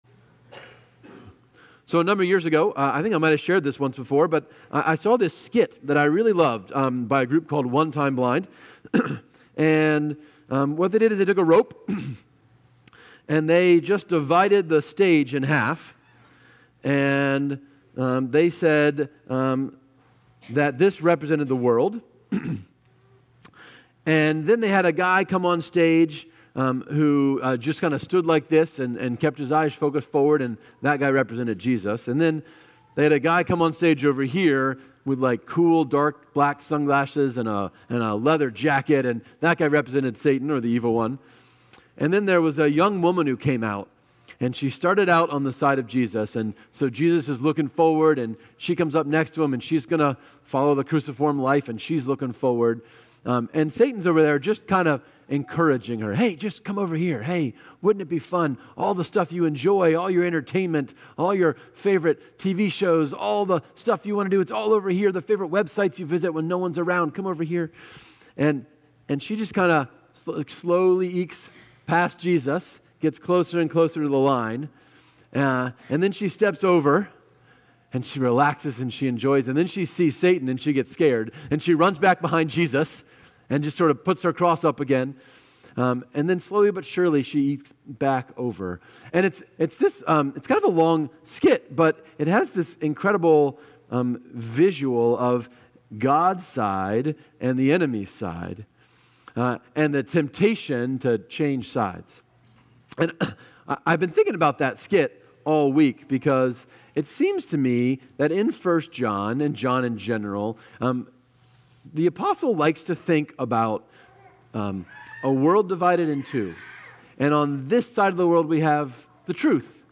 6-15-25+Sermon.mp3